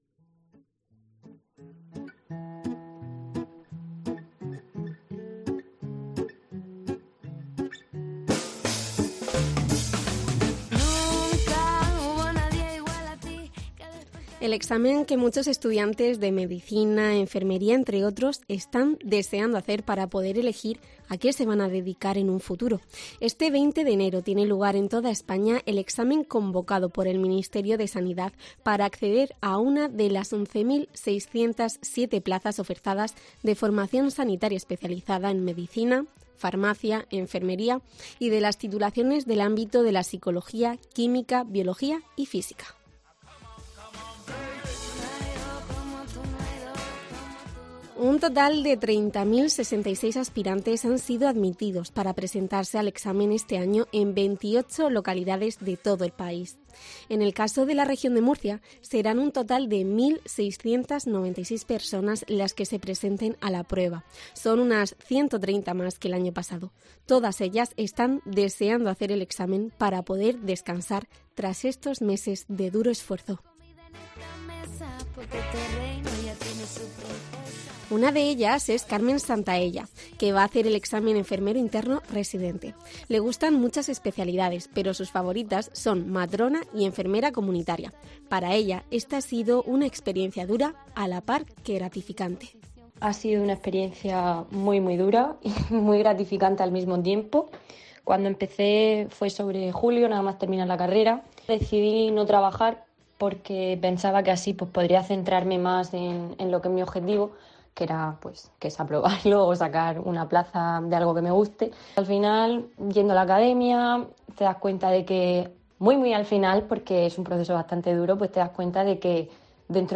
Región de Murcia